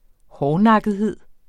Udtale [ ˈhɒːnɑgəðˌheðˀ ]